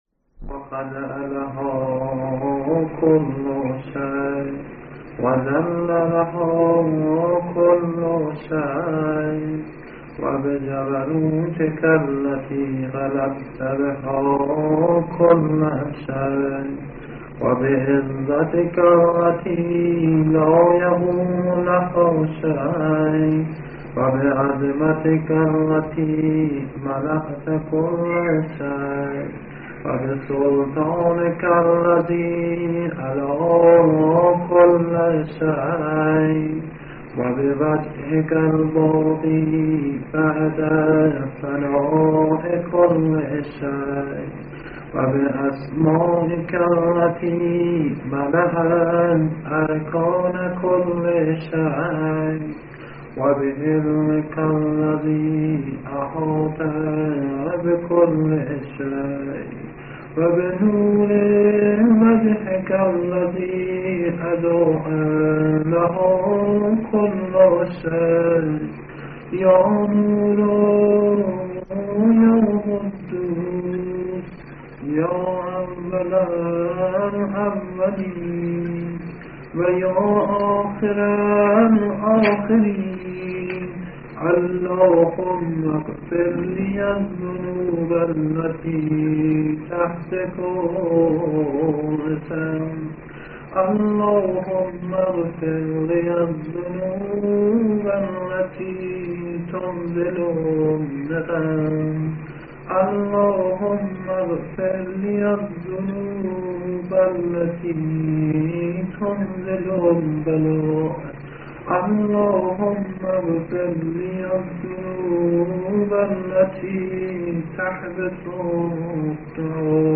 نوای مداحی